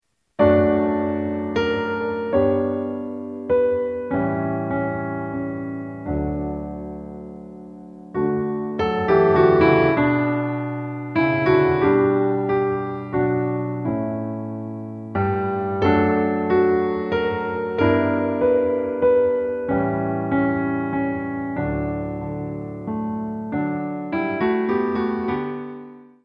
A Ballet Class CD